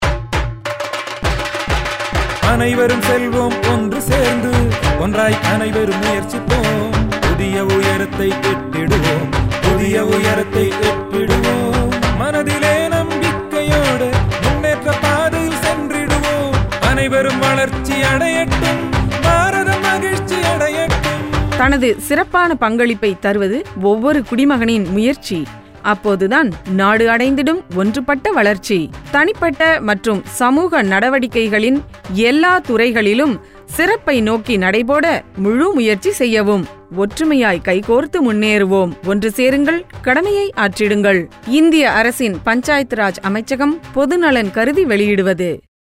221 Fundamental Duty 10th Fundamental Duty Strive for excellence Radio Jingle Tamil